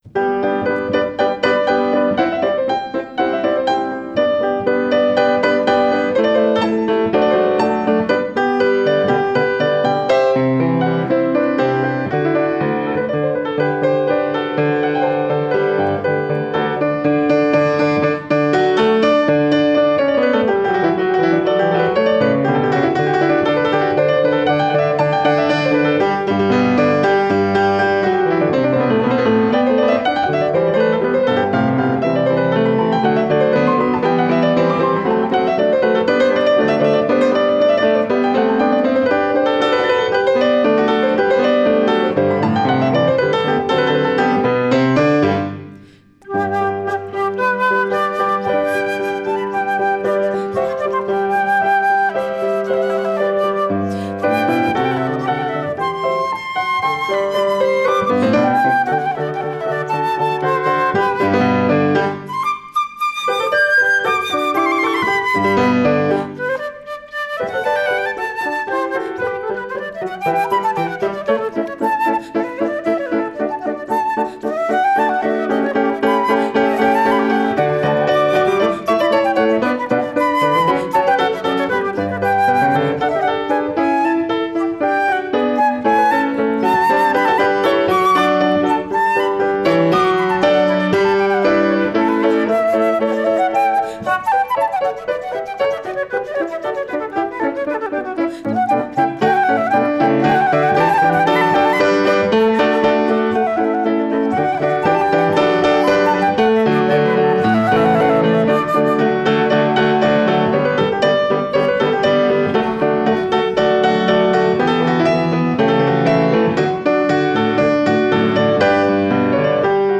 Producción de un CD con las obras interpretadas por los estudiantes de recitales, ensamble de jazz, música ecuatoriana y música Latinoamericana de la Universidad de Los Hemisferios del semestre 2017-1 de mayor calidad sonora y mejor ejecución musical